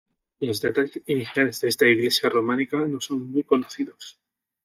Pronounced as (IPA) /konoˈθidos/